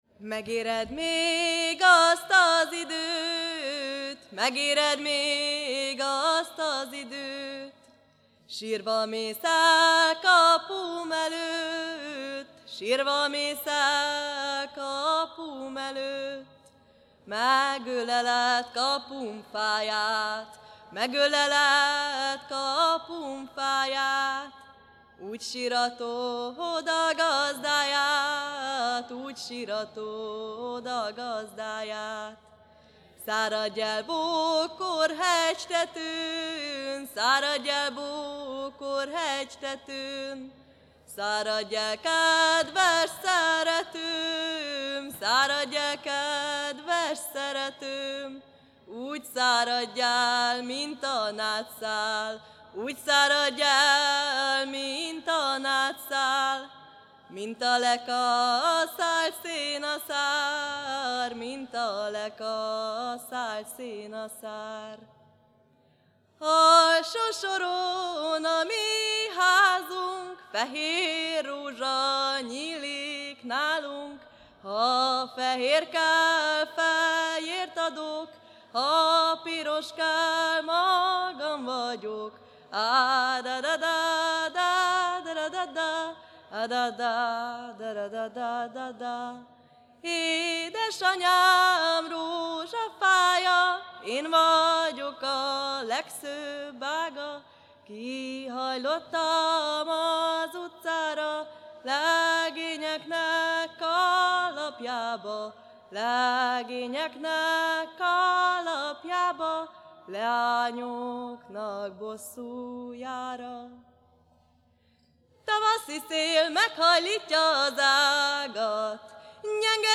A Jégcsarnokban rögzített hanganyag
Mezőségi népdalok